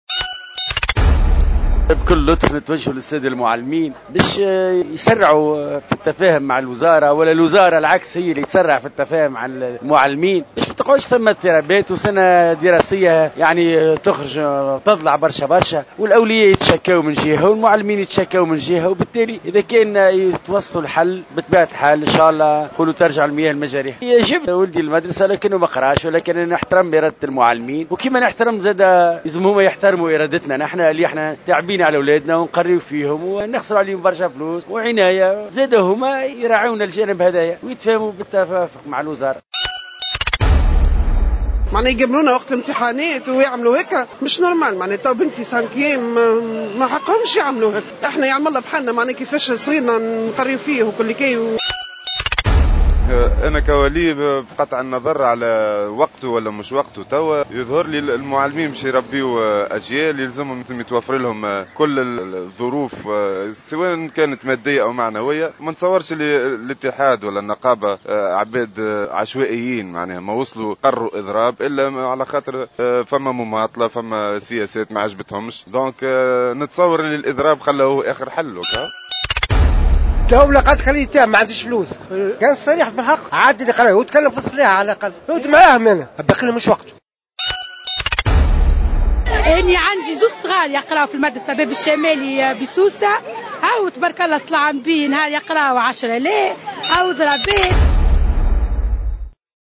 اضراب المعلمين : أراء الأولياء تنقسم بين مؤيد ورافض (روبورتاج)